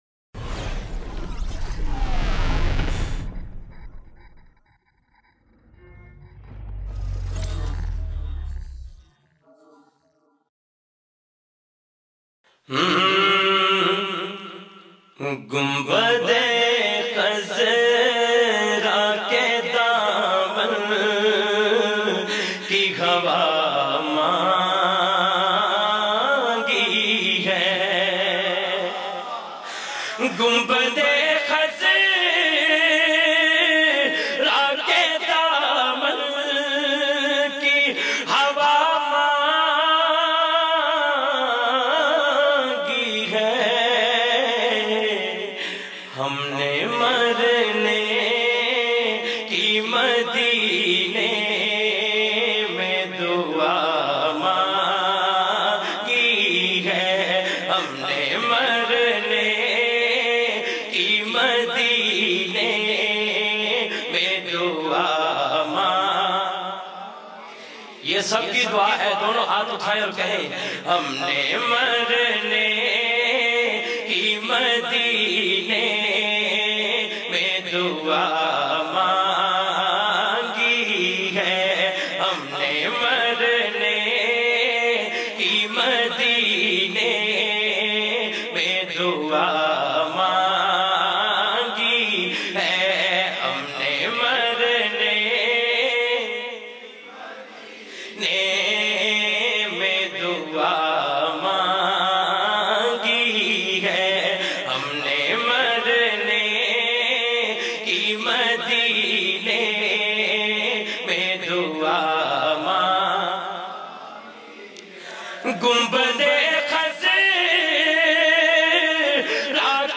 in best audio quality.